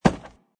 woodgrass3.mp3